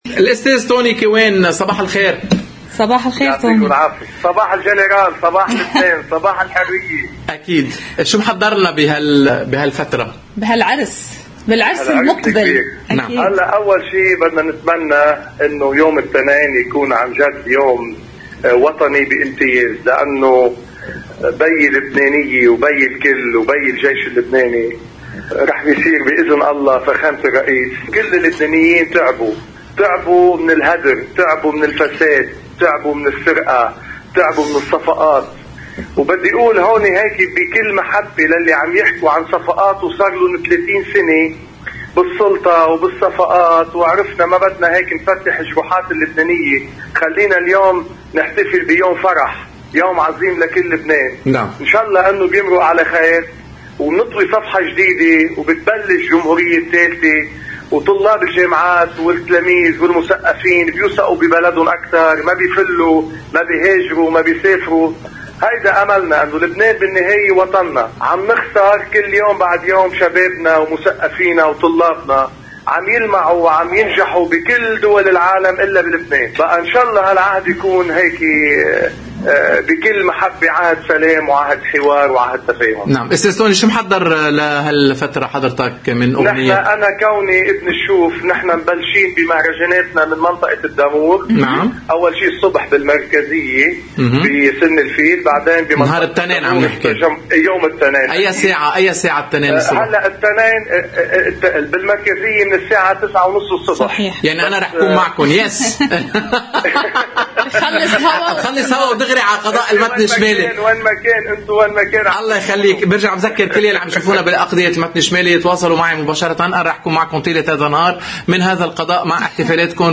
في اتصال مع قناة الـ”OTV” قال الفنان الكبير طوني كيوان، عن يوم الإثنين الكبير: